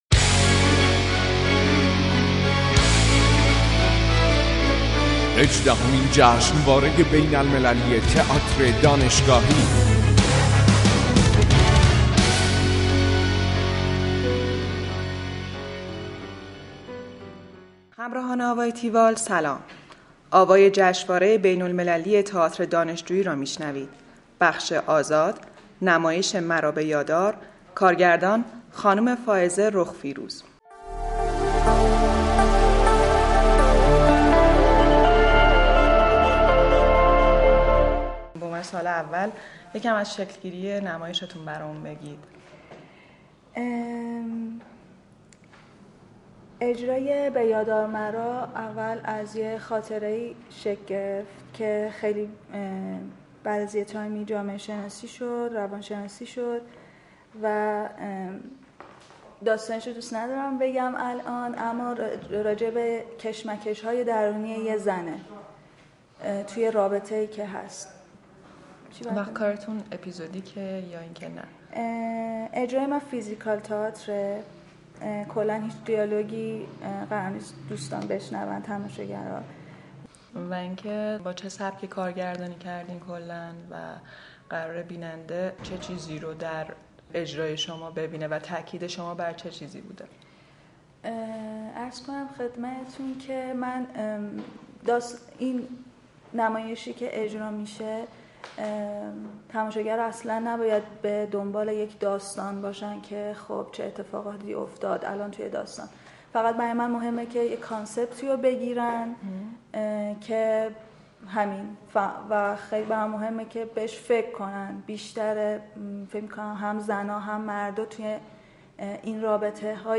گفتگو کننده